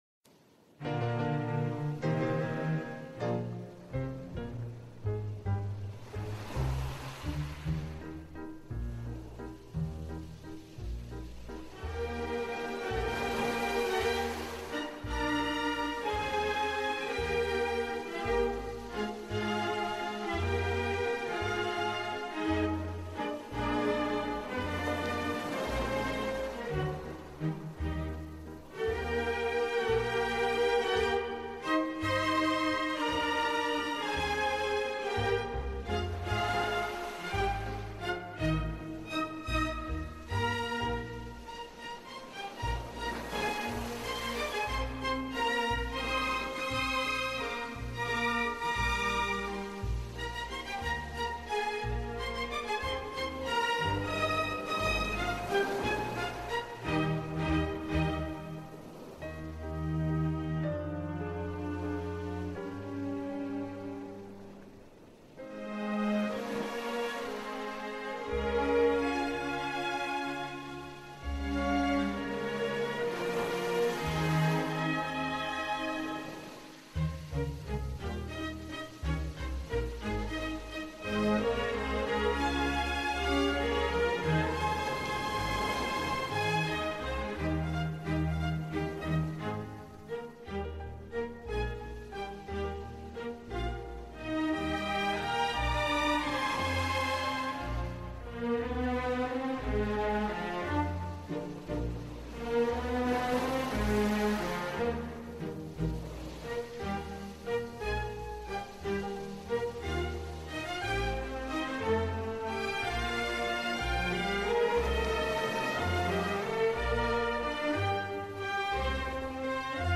Pluie Naturelle : Chambre Apaisée